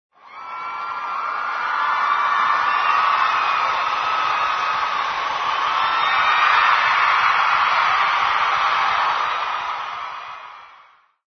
MULTITUD MULTITUD
Ambient sound effects
Descargar EFECTO DE SONIDO DE AMBIENTE MULTITUD MULTITUD - Tono móvil
Multitud_multitud.mp3